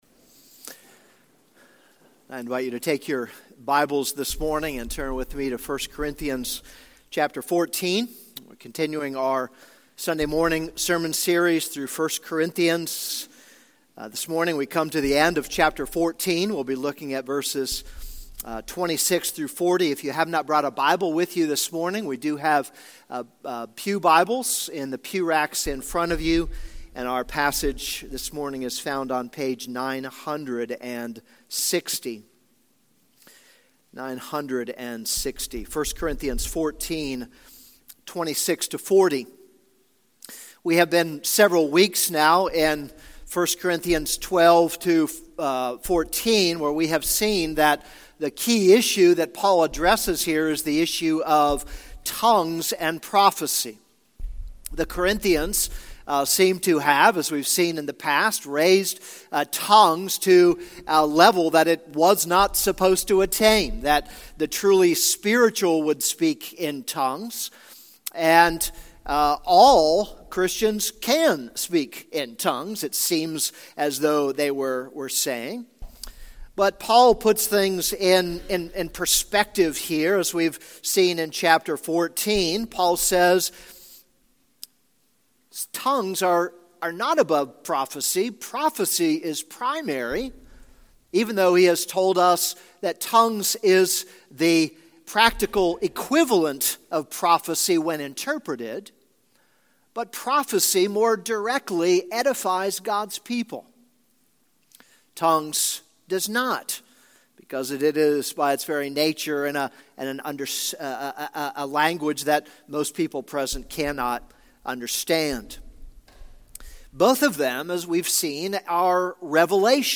This is a sermon on 1 Corinthians 14:26-40.